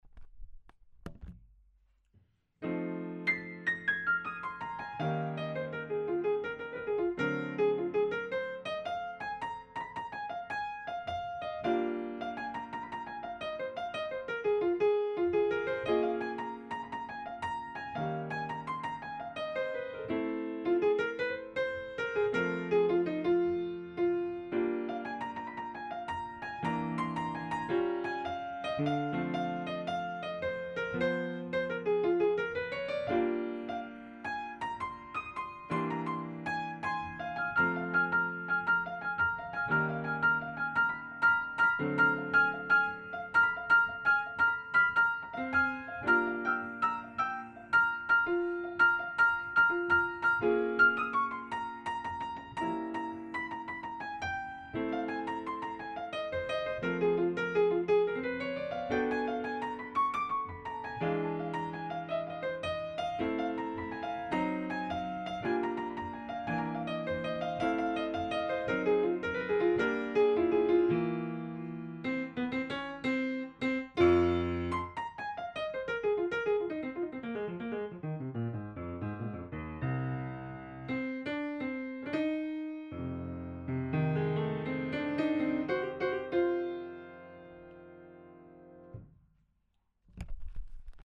version maladroite et strictement pentatonique